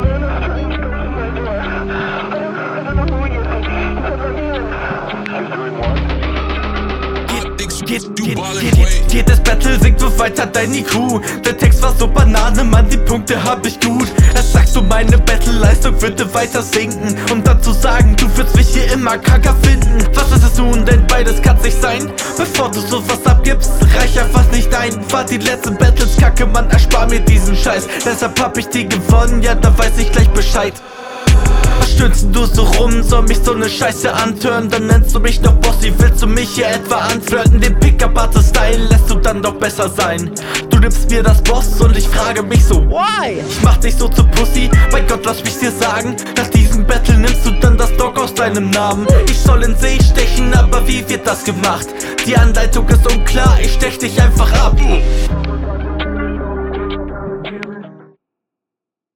Kommst flowlich etwas schwächer als der Gegner, aber ansonsten sehr gleich auf.
Kreativitätspunkt für den Introstutter.